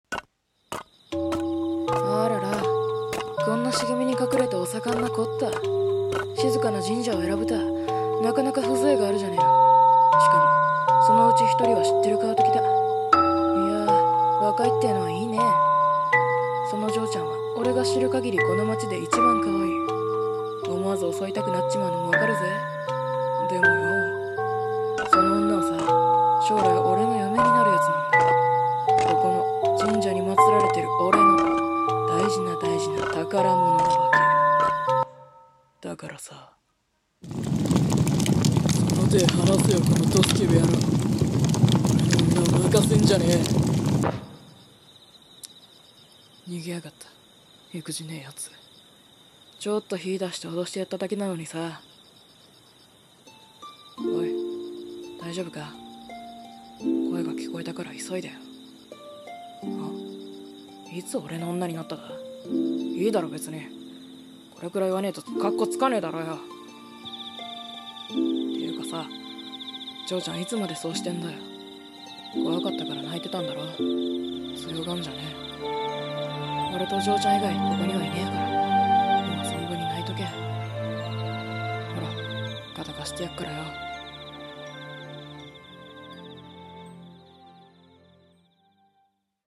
声劇 化け狐と夏の神社